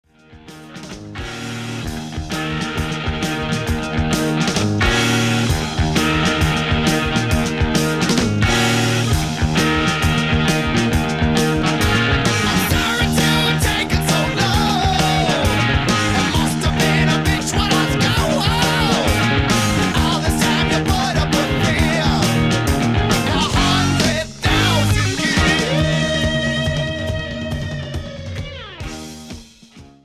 Recorded Location:Bell Sound Studios, New York City
Genre:Hard Rock, Heavy Metal